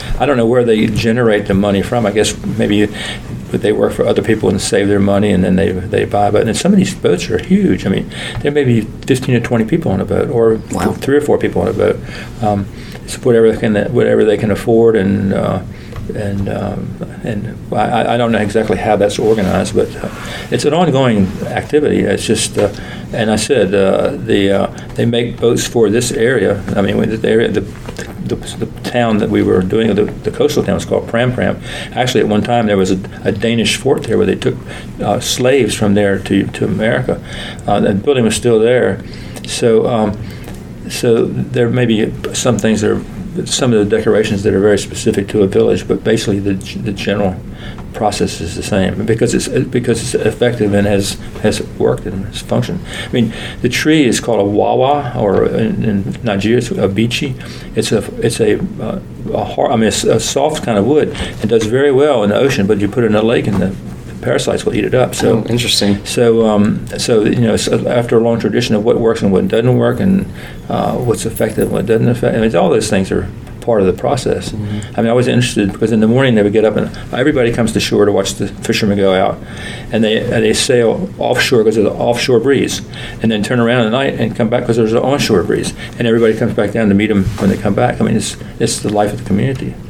The builder